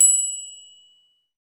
PRC_Brass Bell x